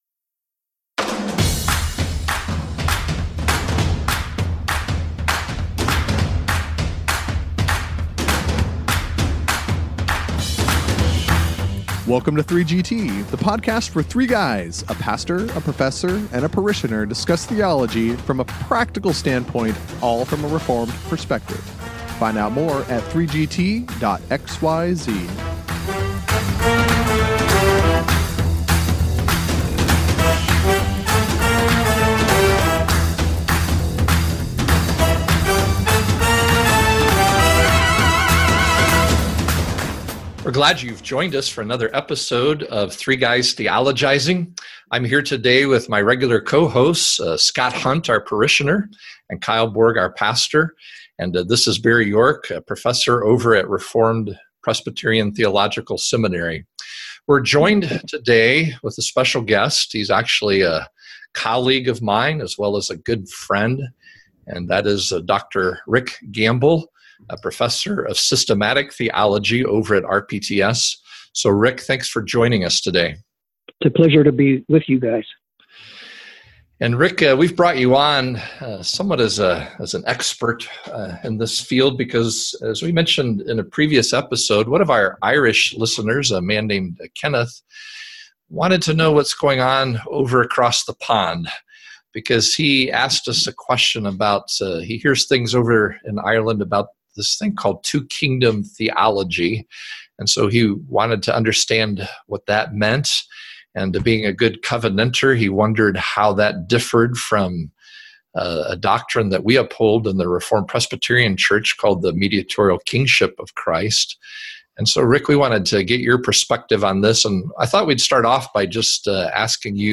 And for this episode, they have added a fourth guy to help them do so.